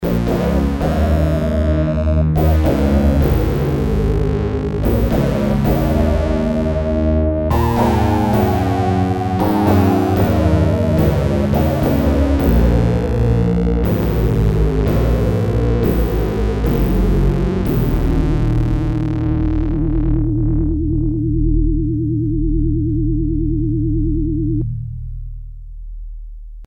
Class: Synthesizer
Synthesis: FM